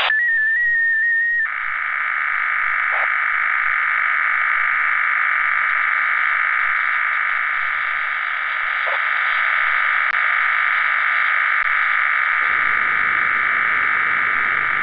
Начало » Записи » Радиоcигналы классифицированные
Запись сигнала OFDM-15